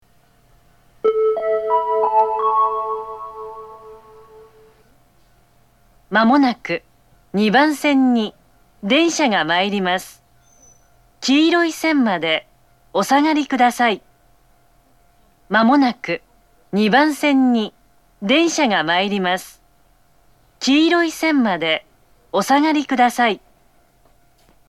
（女性）
接近放送
巌根型の接近放送です。女性の放送です。